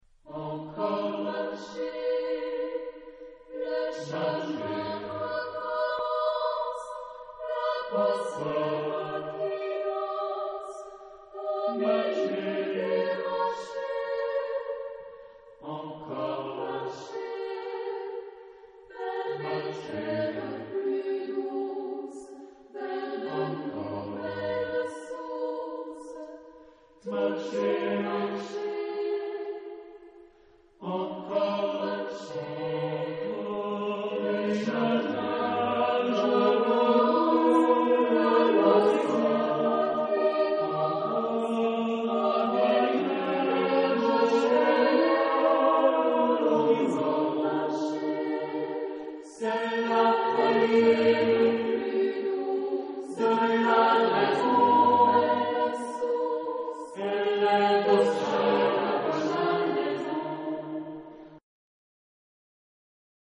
Genre-Style-Forme : Profane ; Quodlibet
Caractère de la pièce : enthousiaste ; désolé
Type de choeur : SSATBB  (6 voix mixtes )
Solistes : Baryton (1)  (1 soliste(s))
Tonalité : sol mineur